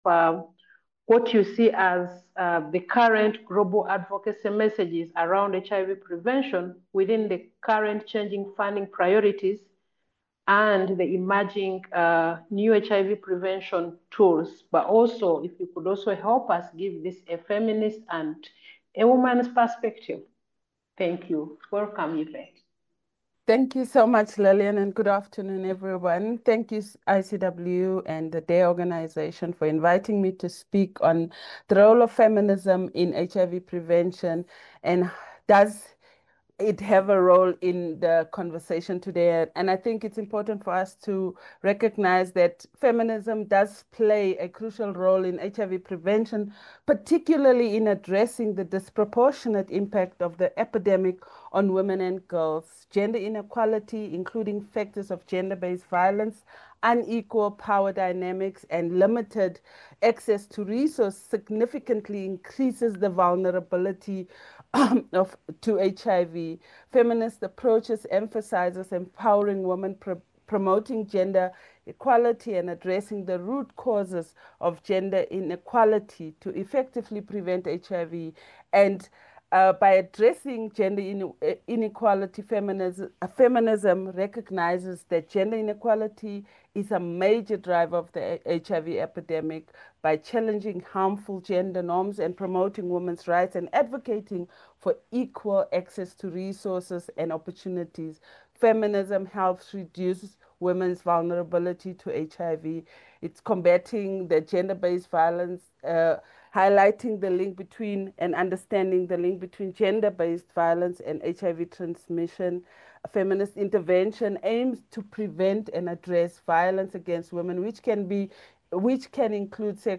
HIV-Prevention-Webinar-Feminist-Lens.mp3